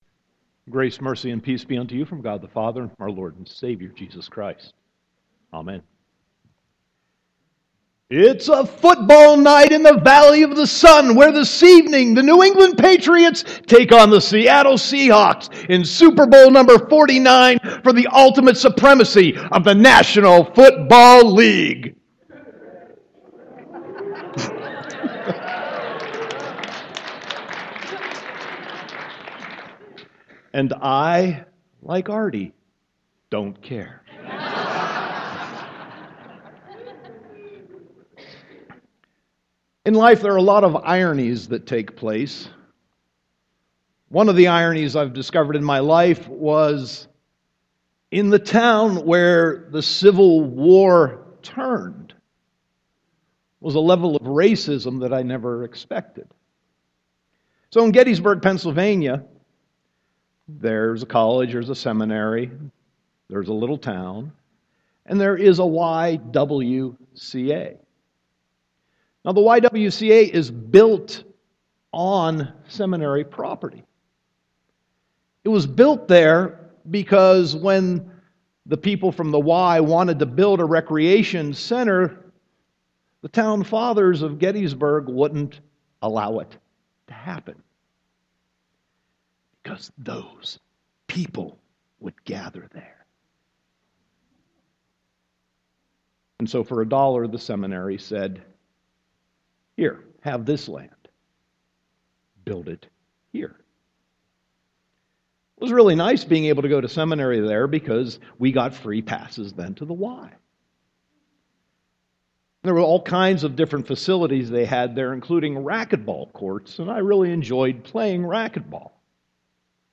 Sermon 2.1.2015